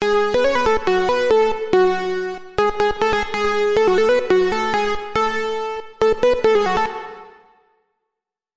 Trance/ EDM Loop (140 BPM)
描述：使用病毒C和第三方效果创建的140 BPM音乐循环。
Tag: 140-BPM 回路 音乐 样品 EDM 恍惚间 舞蹈